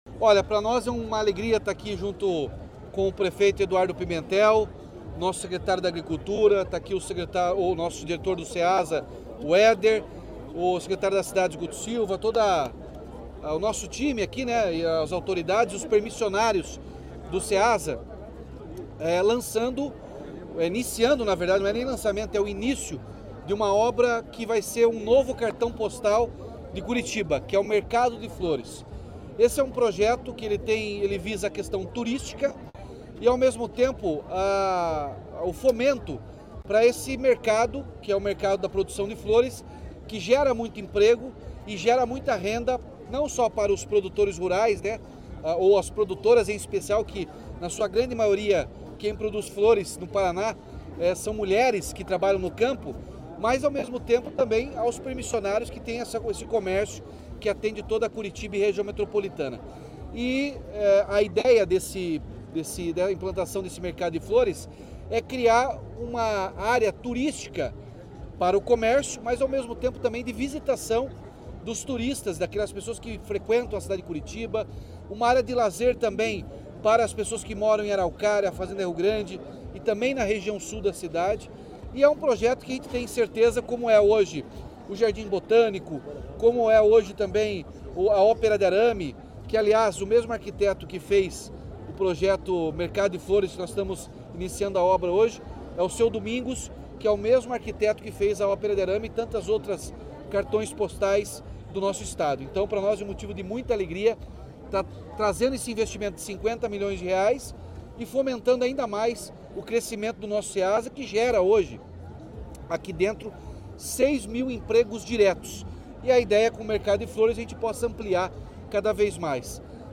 Sonora do governador Ratinho Junior sobre o anúncio do novo Mercado de Flores da Ceasa